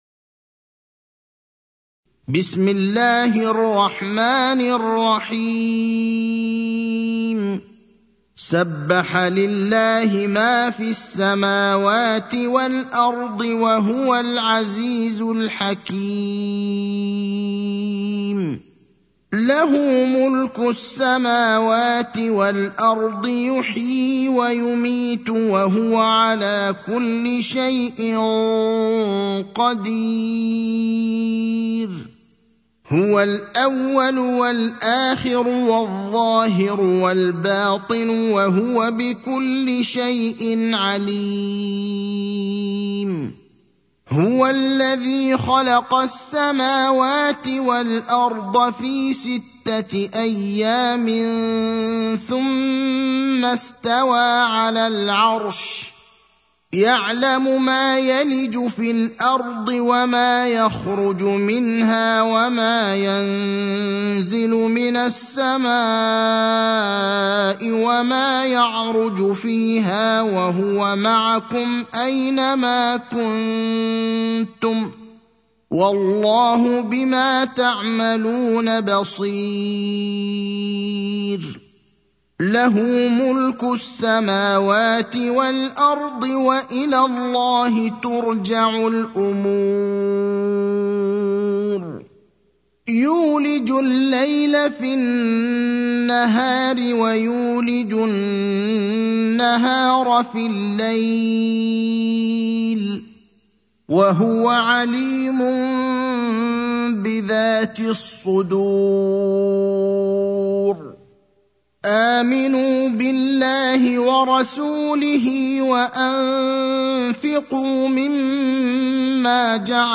المكان: المسجد النبوي الشيخ: فضيلة الشيخ إبراهيم الأخضر فضيلة الشيخ إبراهيم الأخضر الحديد (57)